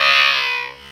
Enemy death sound added for goblins.
goblin_dies.ogg